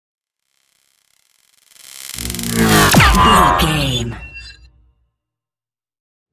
Sci fi shot whoosh to hit 740
Sound Effects
heavy
intense
aggressive
hits